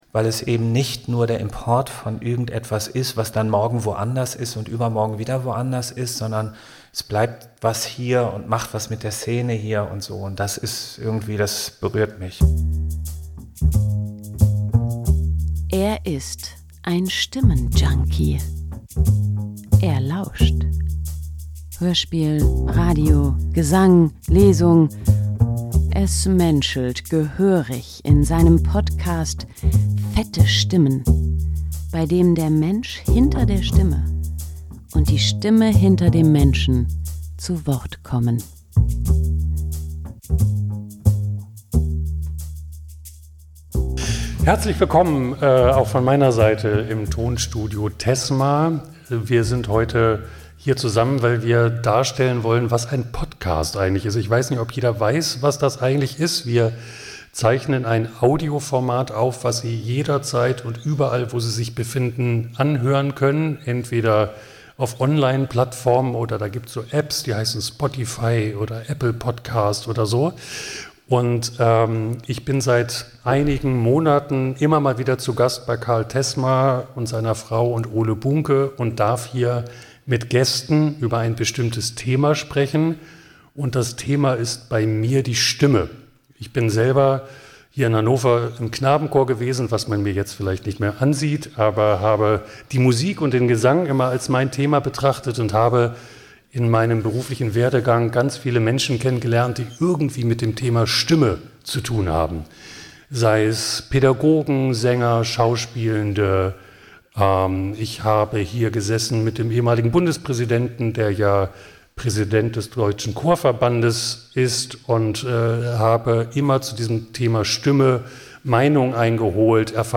PODCAST vor Publikum +++ PODCAST vor Publikum +++ PODCAST vor Publikum